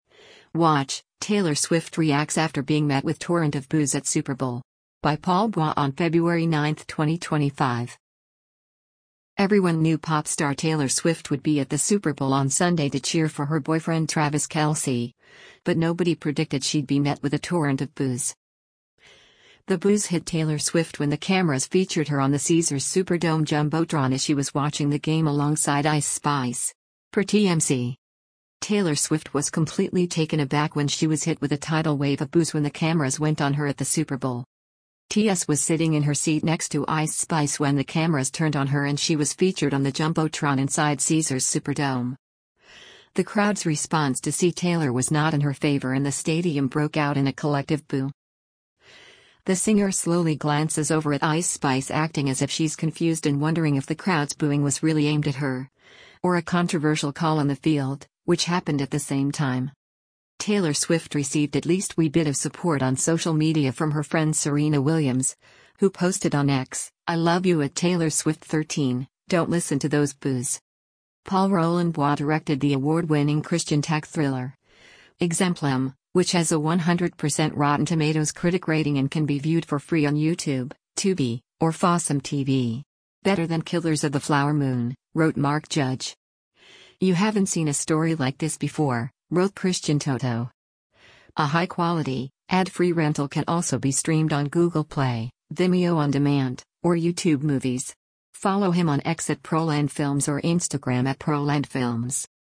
The boos hit Taylor Swift when the cameras featured her on the Caesars Superdome jumbotron as she was watching the game alongside Ice Spice.
Taylor Swift was completely taken aback when she was hit with a tidal wave of boos when the cameras went on her at the Super Bowl.
The crowd’s response to see Taylor was not in her favor and the stadium broke out in a collective boo.